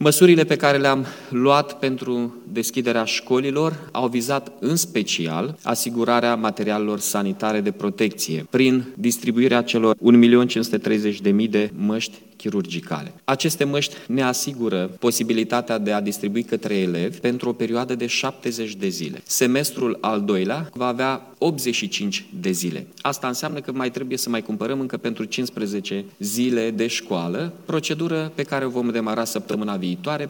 Primarul Iașului spune că școlile nu au probleme cu instalațiile de încălzire și au toate materialele sanitare necesare. Măștile asigurate de primărie le ajung pentru 70 din cele 85 de zile ale semestrului al doilea, a precizat Mihai Chirica: